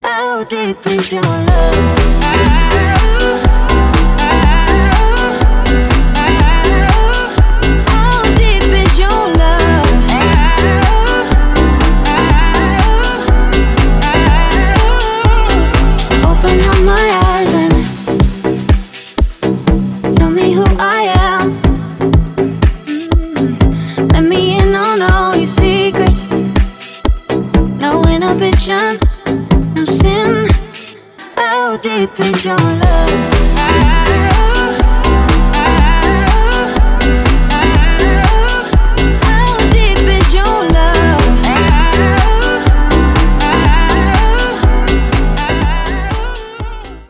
dance
club